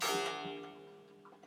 ding3.aiff
clock sound effect free sound royalty free Sound Effects